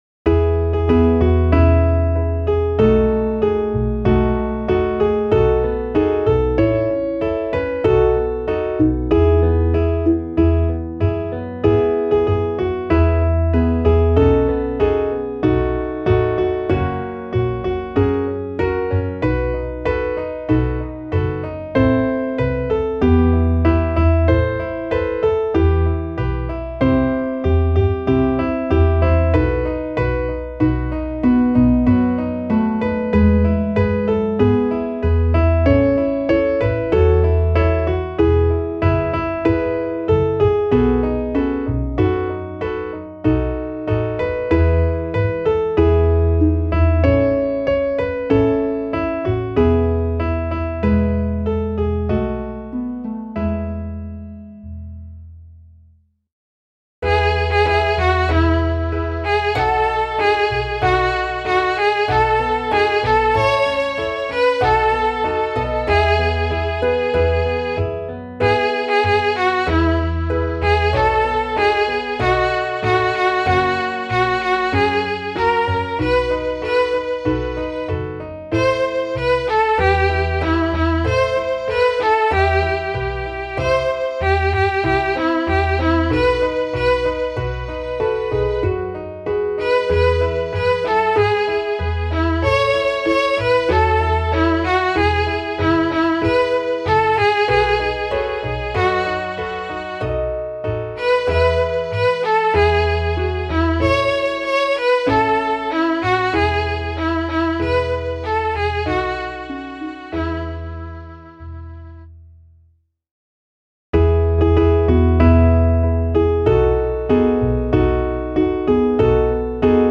midi_in-das-weite_klavier_320.mp3